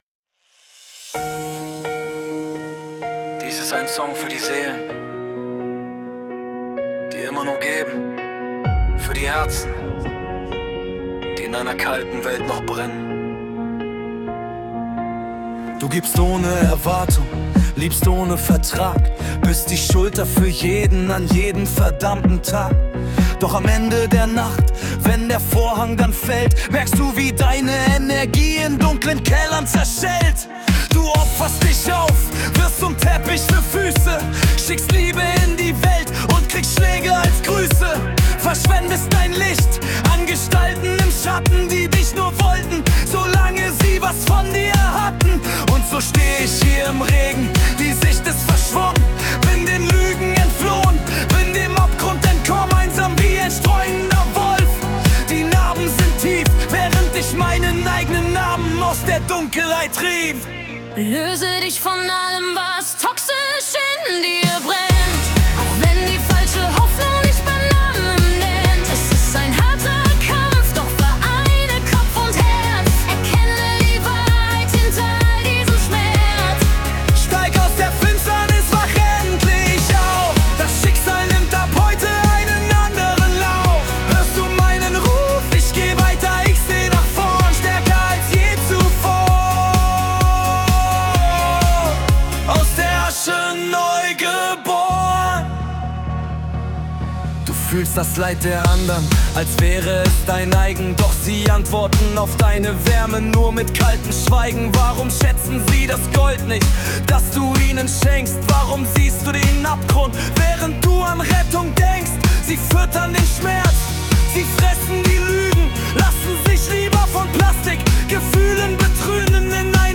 House, PartyMix, MaleFemale Duett